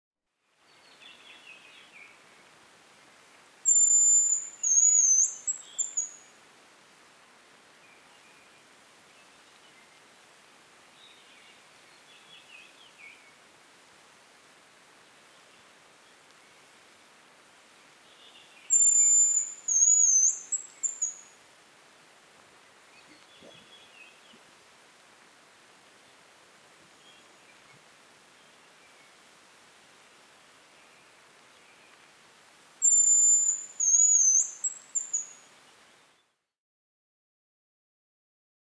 Звуки рябчика
Манок на рябчика для успешной охоты